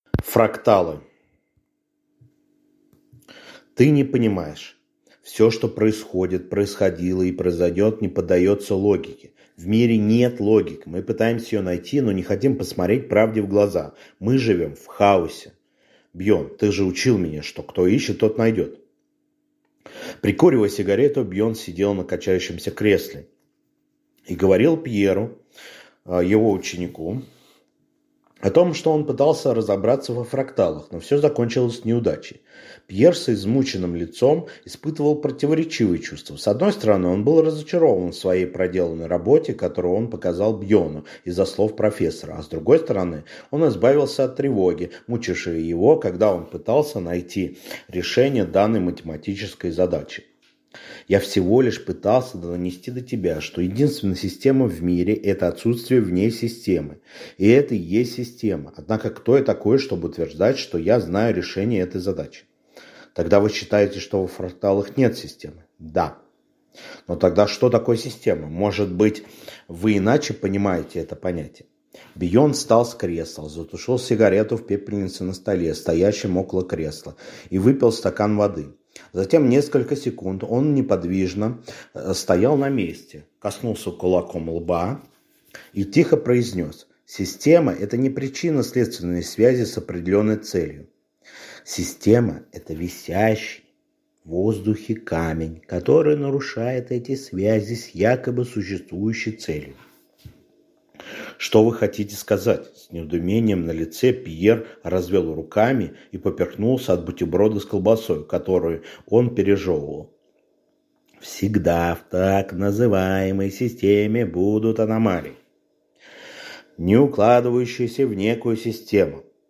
Аудиокнига Фракталы | Библиотека аудиокниг